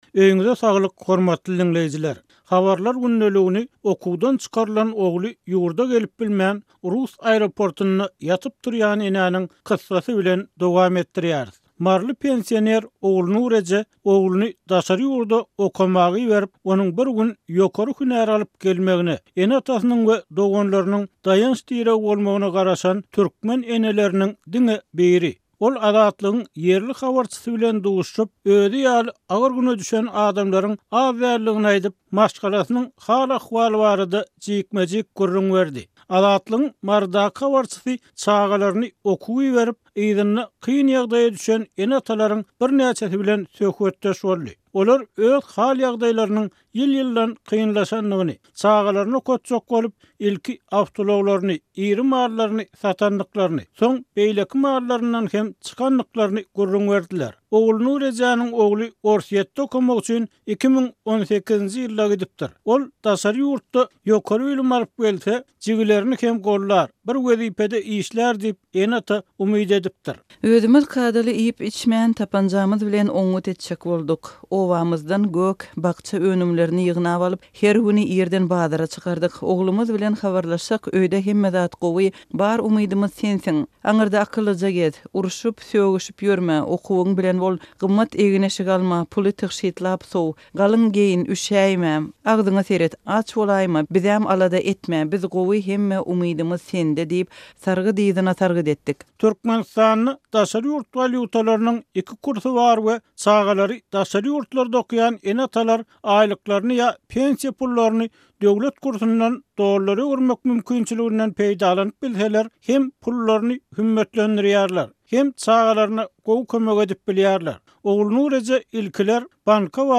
Ol Azatlygyň ýerli habarçysy bilen duşuşyp, özi ýaly agyr güne düşen adamlaryň az däldigini aýdyp, maşgalasynyň hal-ahwaly hakynda jikme-jik gürrüň berdi.